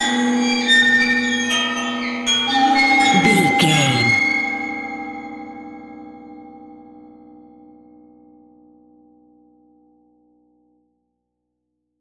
Atonal
ominous
dark
haunting
eerie
creepy
synth
keyboards
ambience
pads